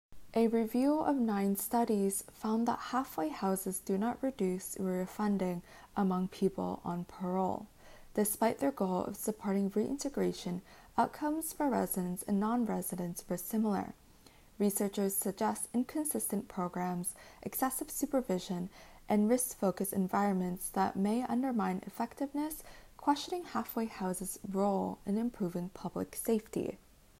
Audio summary